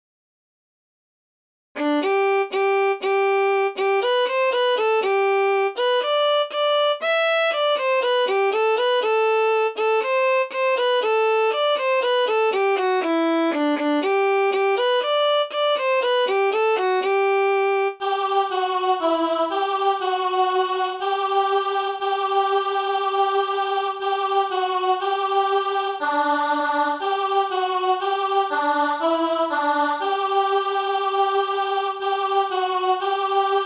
soprano alto
adon_olam_soprano.mp3